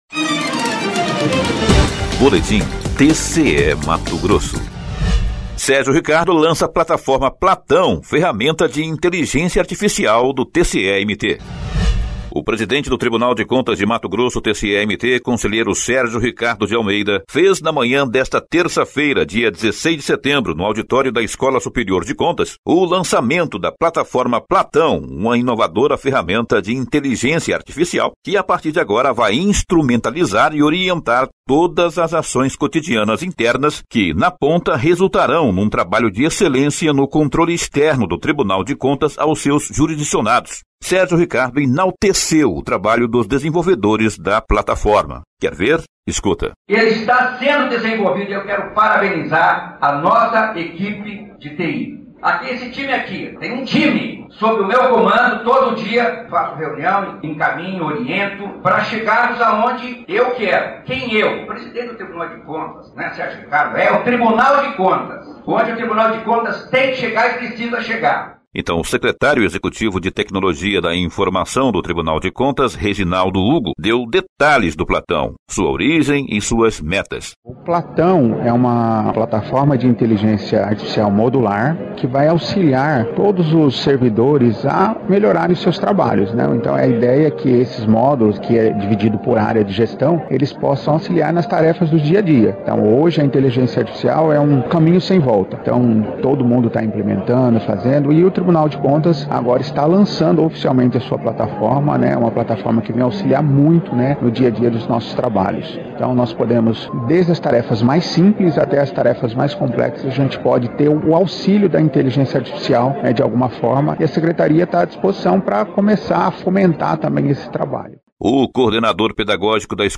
Sonora: Sérgio Ricardo – conselheiro-presidente do TCE-MT